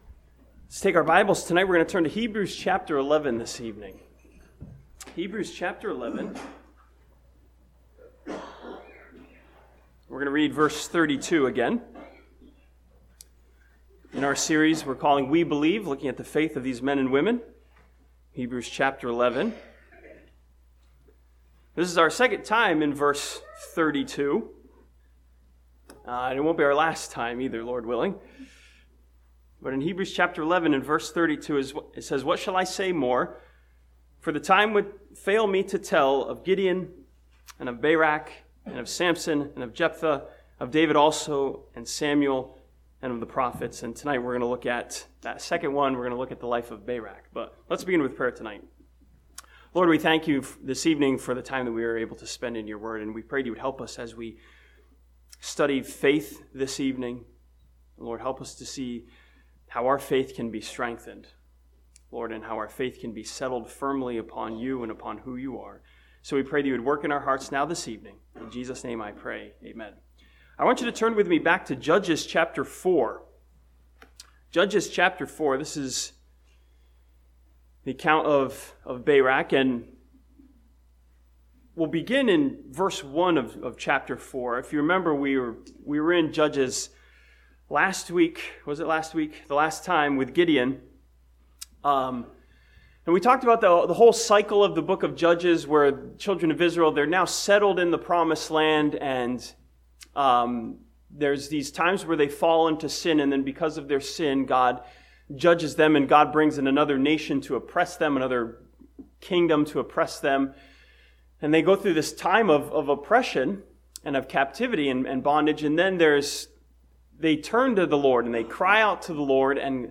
This sermon from Hebrews chapter 11 studies the life and faith of Barak who trusted in the presence of God.